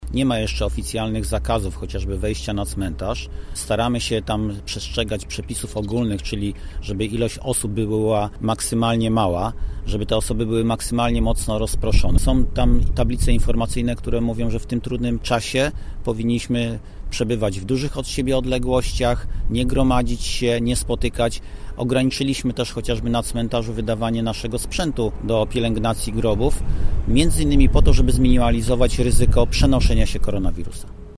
– Nasi mieszkańcy są odpowiedzialni, więc nie utrudniamy tych działań – informuje Roman Siemiński, zastępca burmistrza Krosna Odrzańskiego: